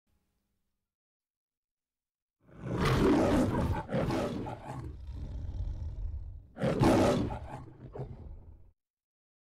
Рык льва в начале Том и Джерри без музыки